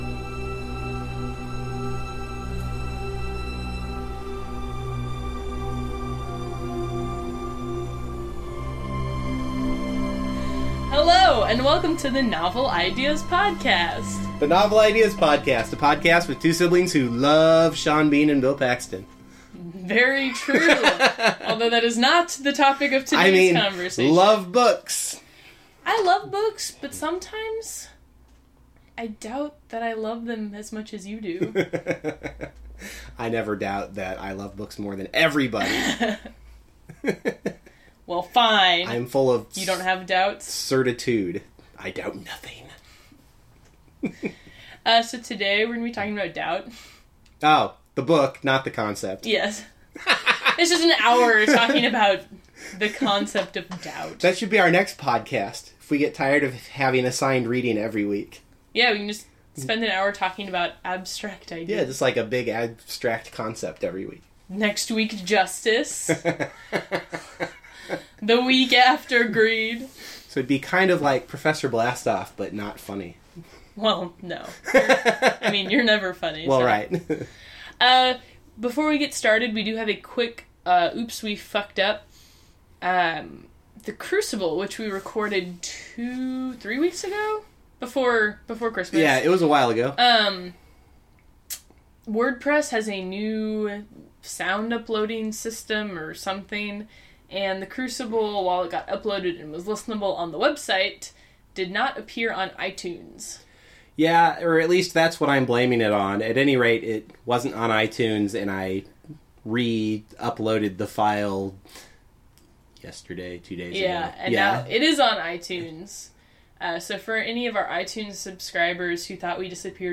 The music bump is “The Unanswered Question” by Charles Ives, a piece with a very fitting title for this play.